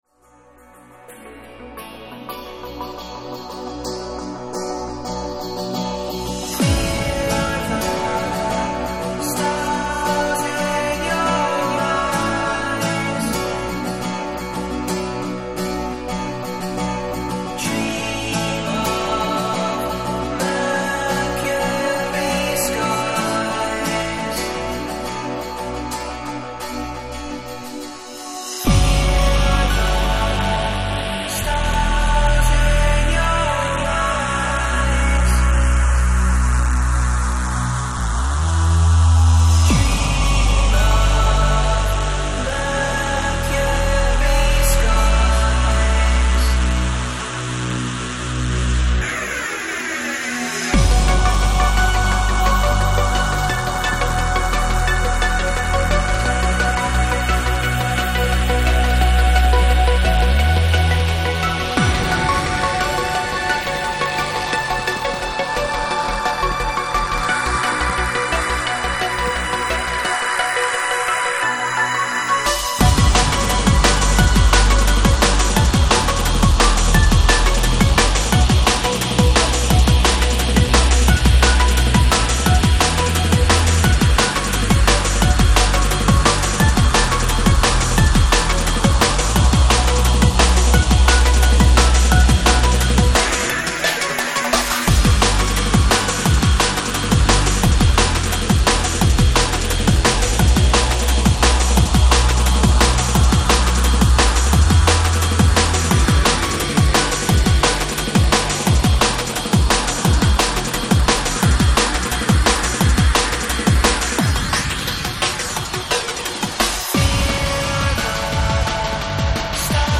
to beautiful Epic Trance-inspired full vocal tracks